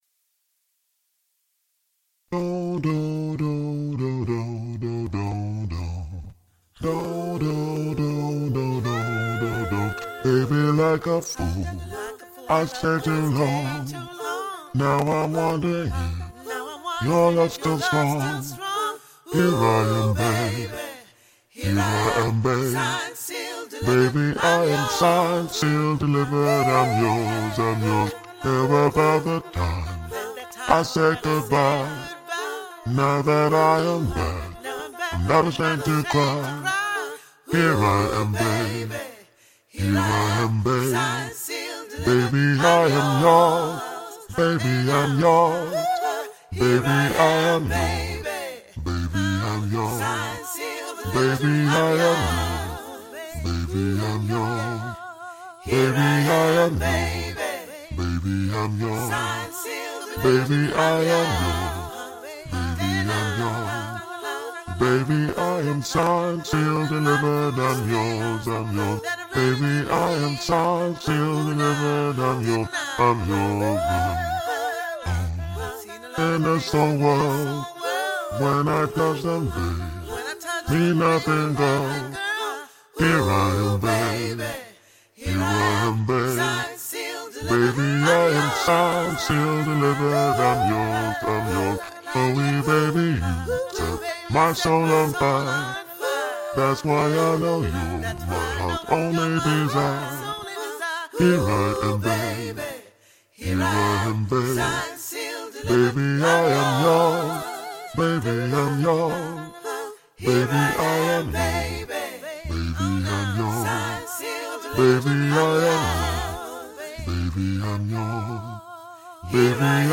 Genre: Choral.